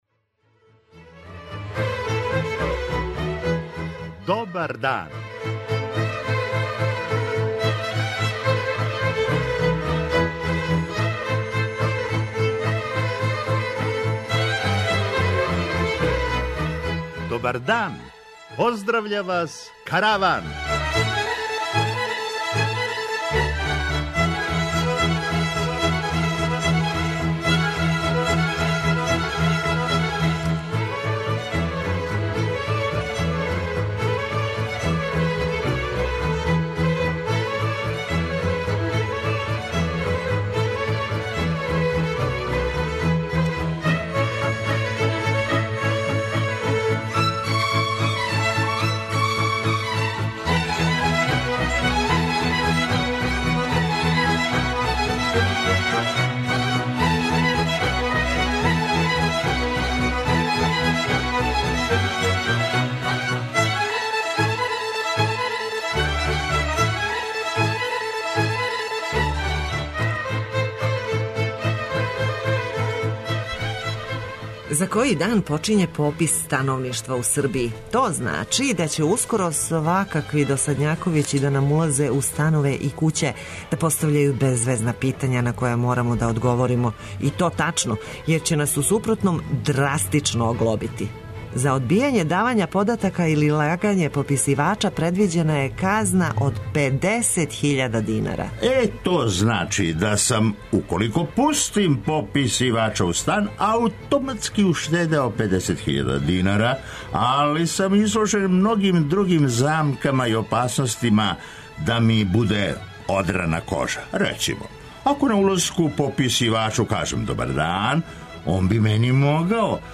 У Каравану ћете чути једну замишљену ситуацију, у којој пописивач пропитује, а испитаник је збуњен, не зна шта да каже, како да јасно дефинише своју тренутну ситуацију, а све у страху не буде кажњен и то са 50 хиљада динара.
преузми : 22.78 MB Караван Autor: Забавна редакција Радио Бeограда 1 Караван се креће ка својој дестинацији већ више од 50 година, увек добро натоварен актуелним хумором и изворним народним песмама.